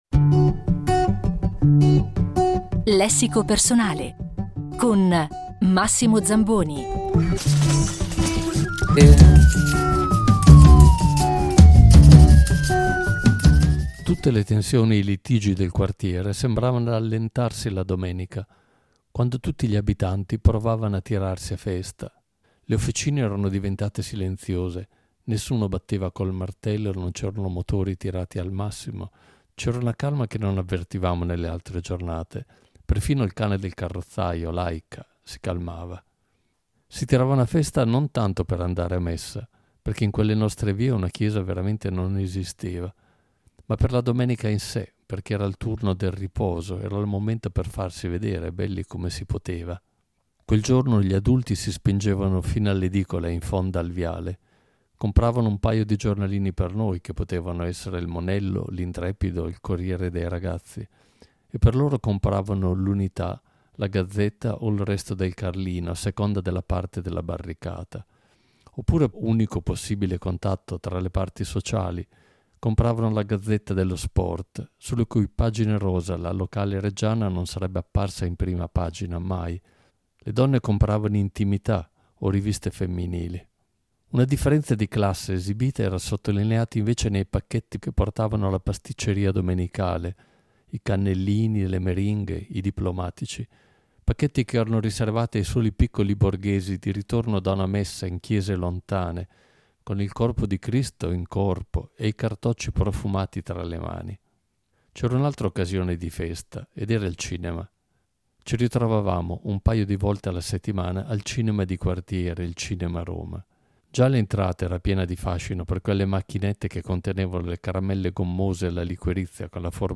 Co-fondatore dei CCCP - Fedeli alla linea e di CSI, musicista e intellettuale, Massimo Zamboni è il protagonista di questa settimana del ciclo estivo Lessico Personale: ci racconterà cinque momenti, cinque parole, cinque istantanee che hanno segnato il corso della sua vita.